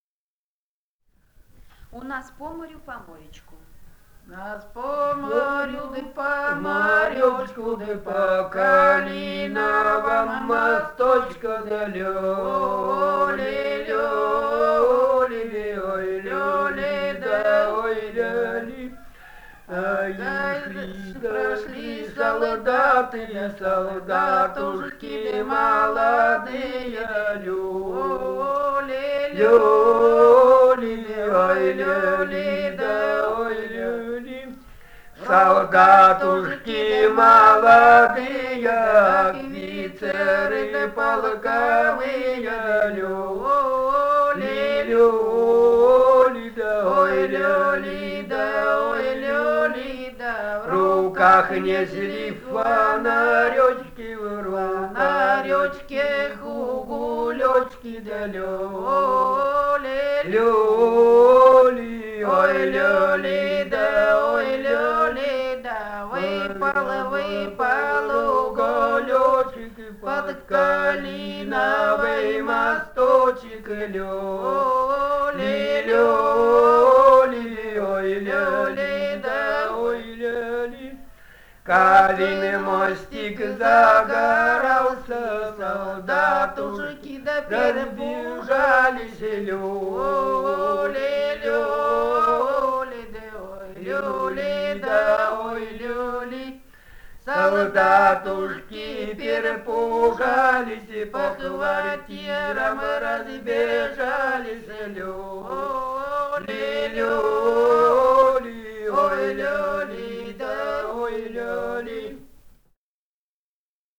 полевые материалы
Алтайский край, с. Михайловка Усть-Калманского района, 1967 г. И1001-08